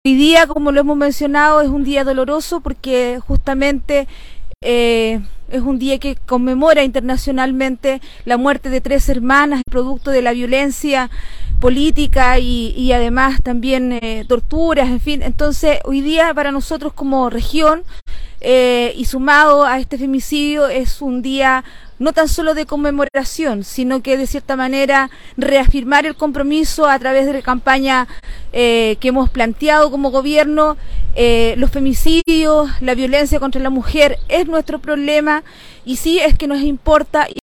El anuncio lo realizó en medio de una actividad en conmemoración del Día Internacional por la Eliminación de la Violencia Hacia la Mujer, realizado en dependencias del Centro de Reeducación de Hombres del Servicio Nacional de la Mujer e Igualdad de Género (Sernameg), en donde participó junto a los secretarios regionales ministeriales que integran el gabinete regional.